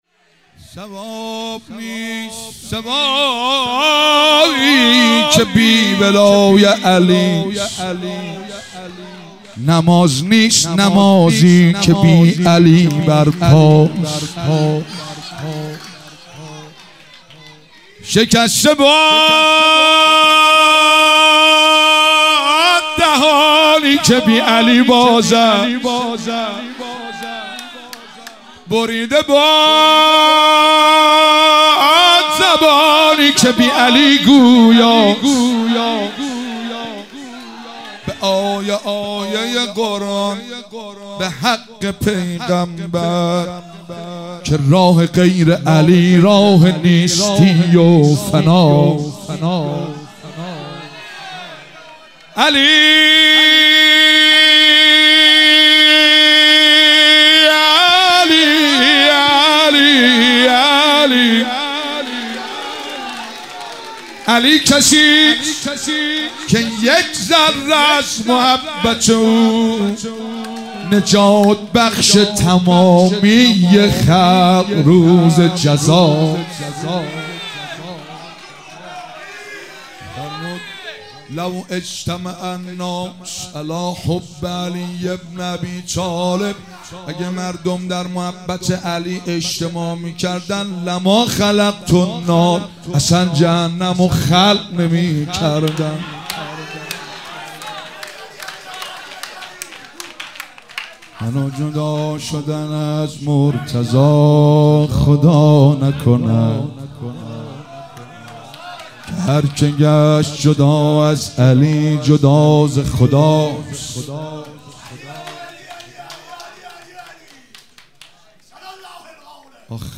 7 شهریور 96 - هیئت قمر بنی هاشم - مدح امام علی علیه السلام
شهادت امام باقر (ع)